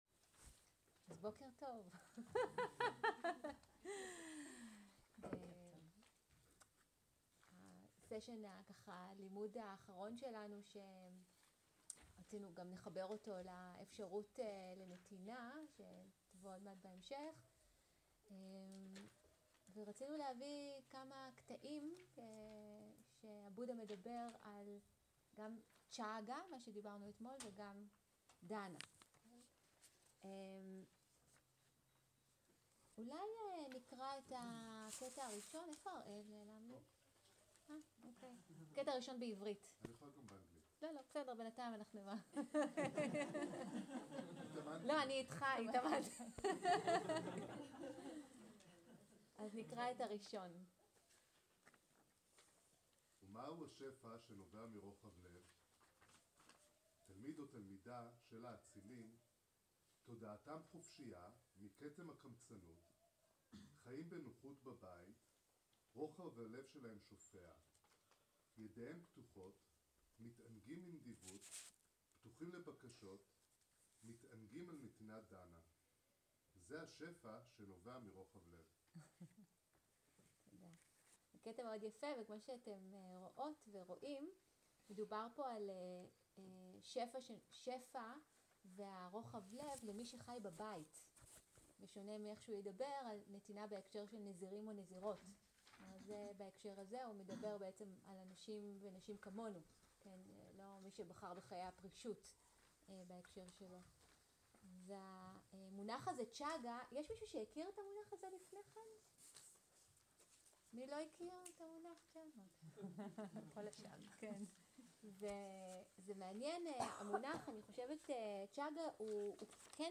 סוג ההקלטה: שיחת דאנה
עברית איכות ההקלטה: איכות גבוהה תגיות